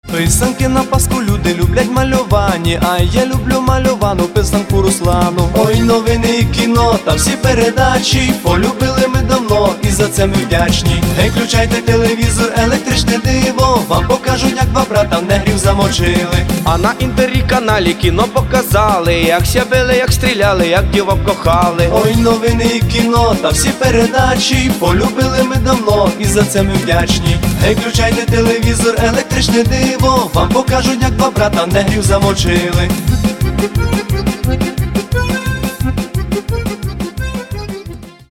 Каталог -> MP3-CD -> Народная